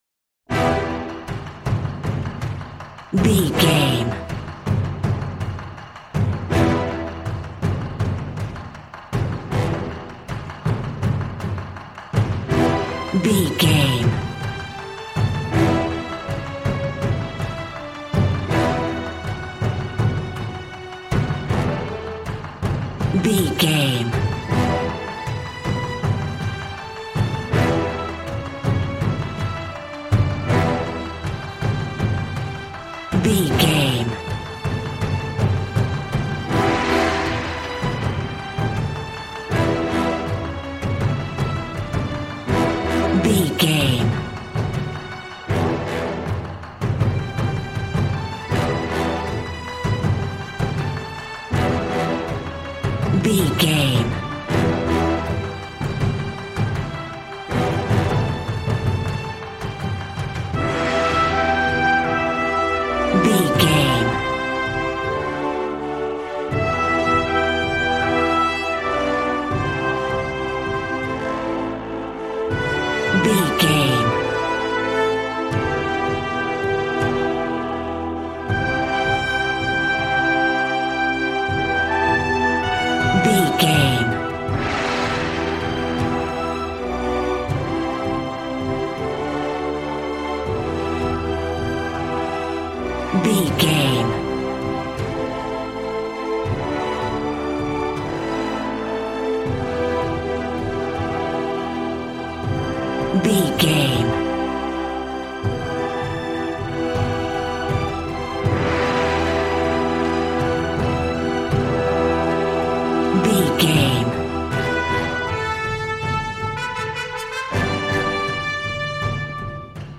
Aeolian/Minor
regal
cello
double bass